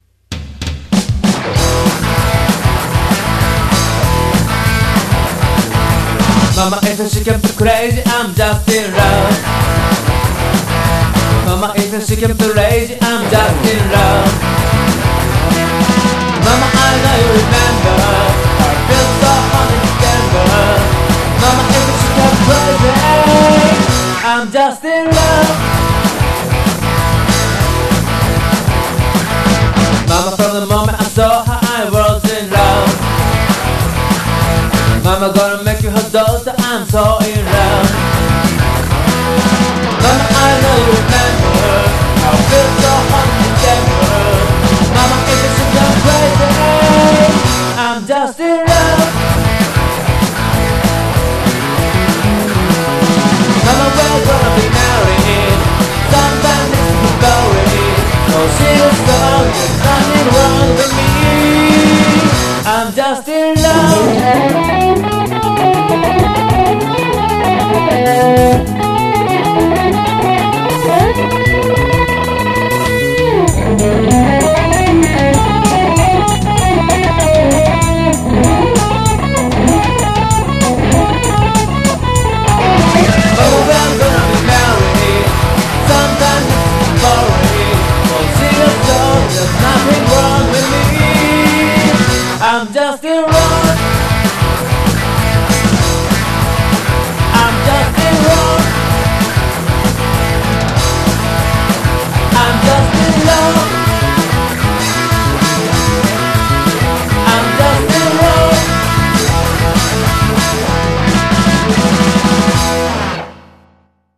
しかも、歌っちゃいました。。コレがまた… ( ´艸｀)